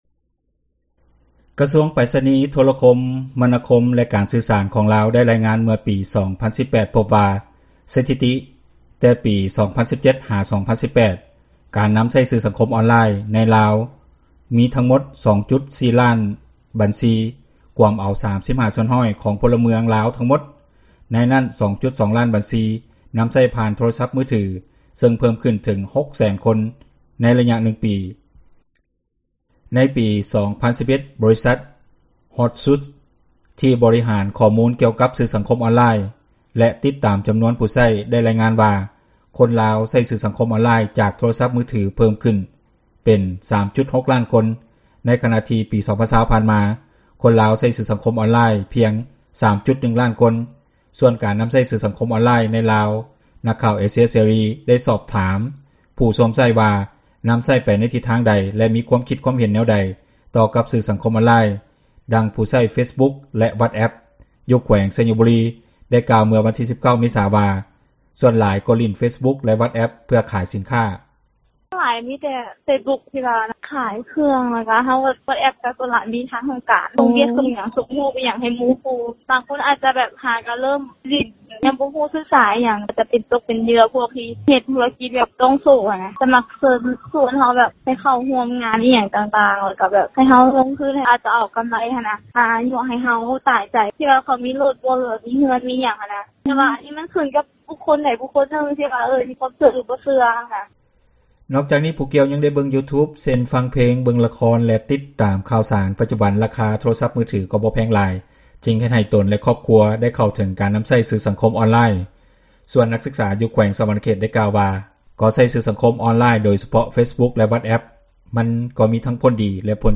ສ່ວນການນໍາໃຊ້ສື່ສັງຄົມອອນລາຍ ໃນລາວ ນັກຂ່າວເອເຊັຽເສຣີ ກໍໄດ້ສອບຖາມຜູ້ໃຊ້ ວ່ານໍາໃຊ້ໄປທິດທາງໃດ ແລະມີຄວາມຄິດ ຄວາມເຫັນແນວໃດ ຕໍ່ກັບສື່ສັງຄົມອອນລາຍ.